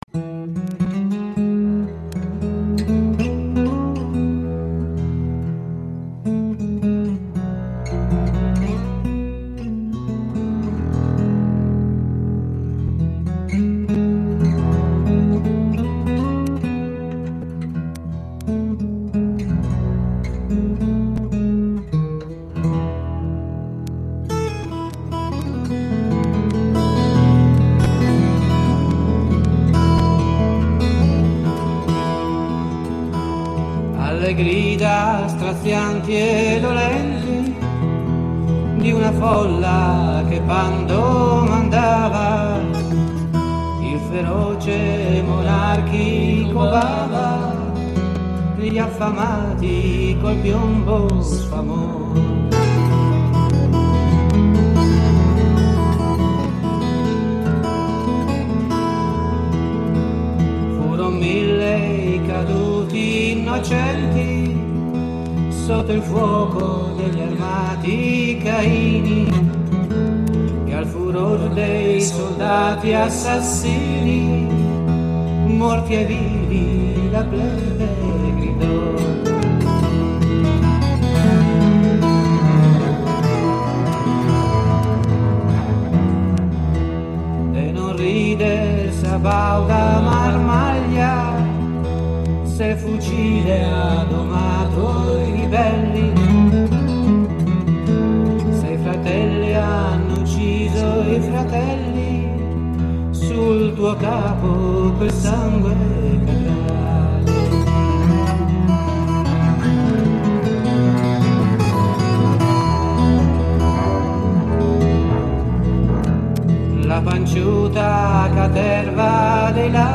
Come gia altre canzoni popolari ne esiste un’altra versione più ampia cui si aggiunge come penultima la seguente strofa:
Per inciso, la melodia di questa vecchia ballata rivoluzionaria tra le più conosciute, sarà utilizzata a Mantova il 21 dicembre 1969 (giorno successivo ai funerali di Giuseppe Pinelli, l’anarchico precipitato da una finestra della questura di Milano nel corso di un interrogatorio per la strage di piazza Fontana) come aria per la canzone “La Ballata dell’Anarchico Pinelli”, divenuta per anni un inno della sinistra extraparlamentare contro i complotti e le stragi di stato.